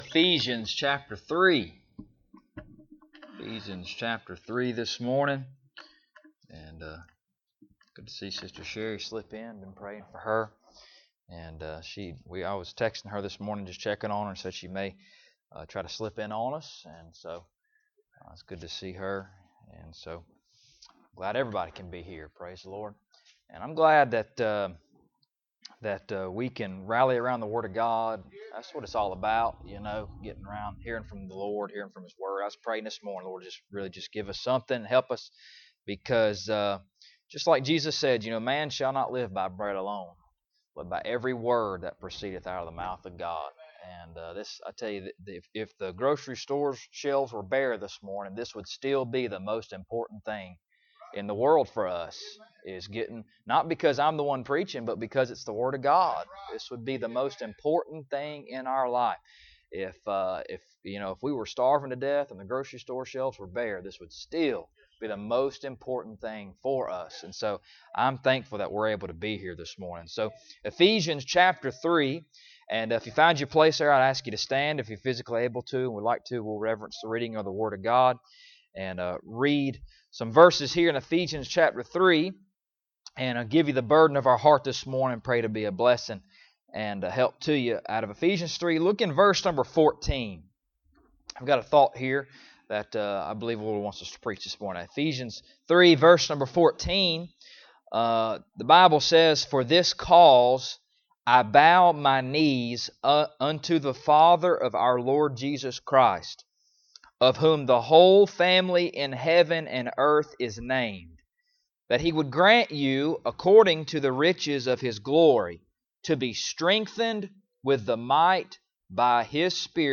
Ephesians 3:14-21 Service Type: Sunday Morning Bible Text